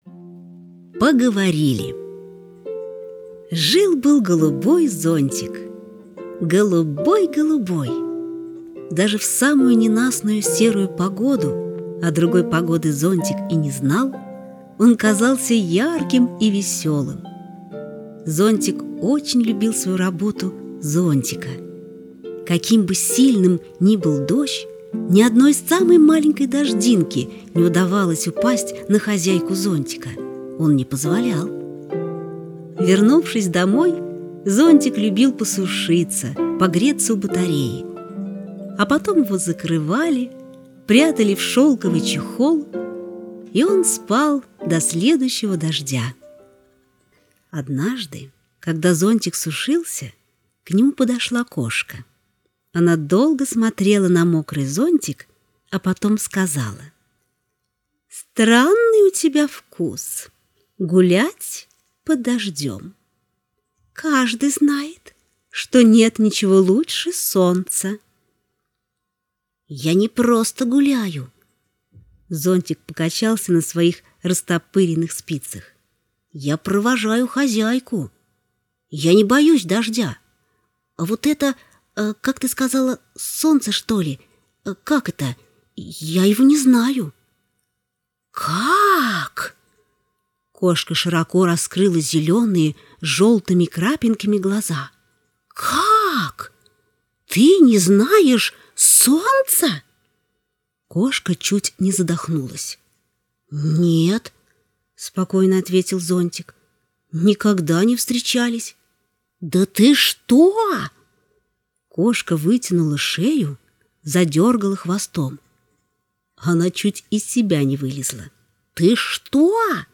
Аудиосказка «Поговорили»